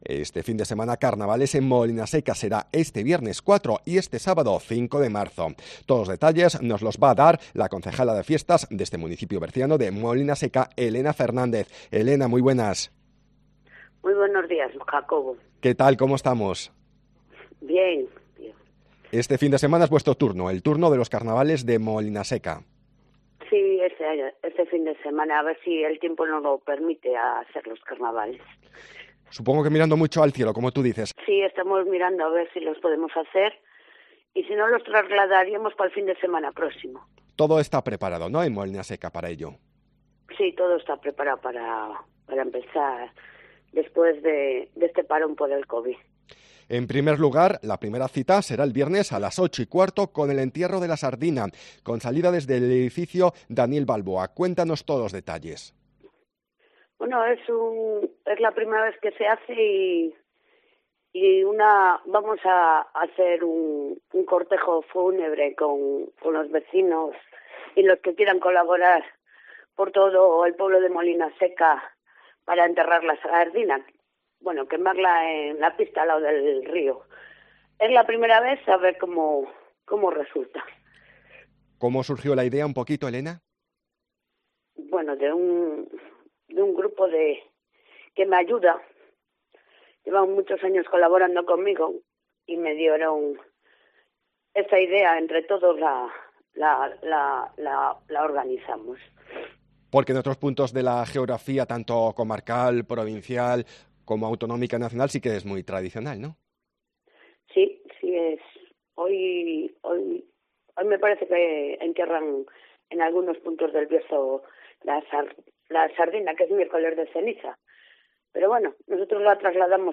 Todos los detalles nos los ha dado la concejala de Fiestas del municipio berciano, Elena Fernández, en el Mediodía COPE Bierzo